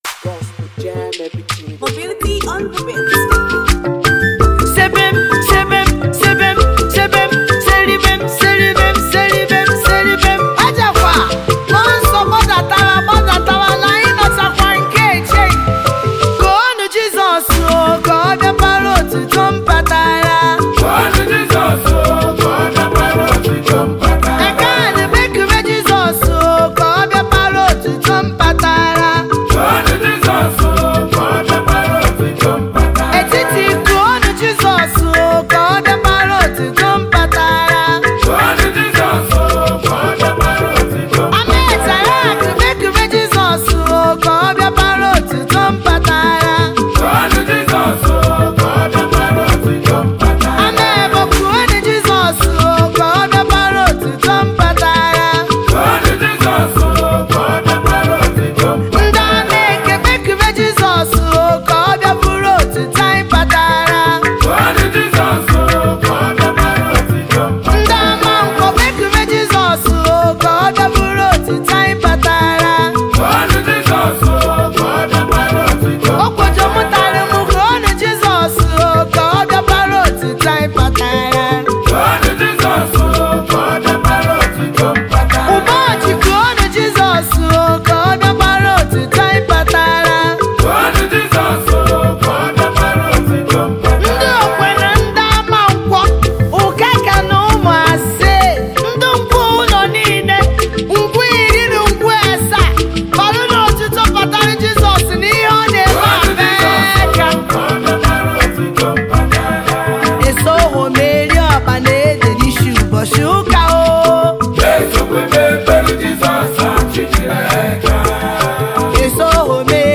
African praisemusic